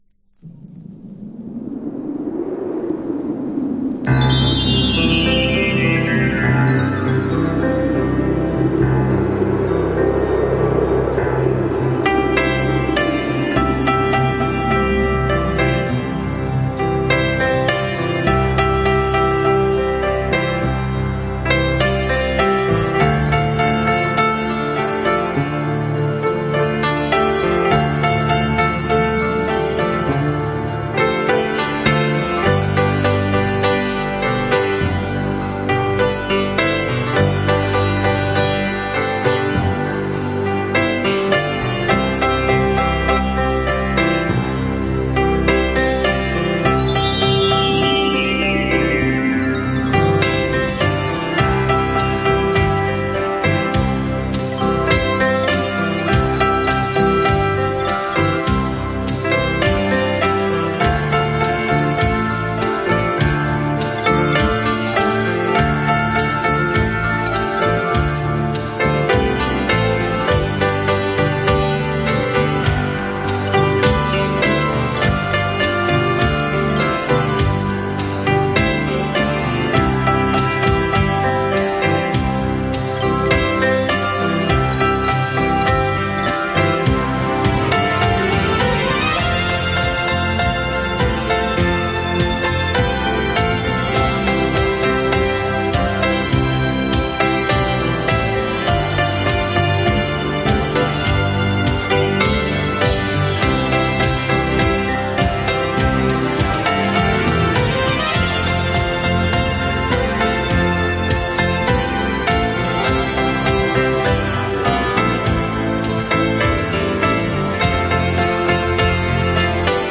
Жанр: Pop, Instrumental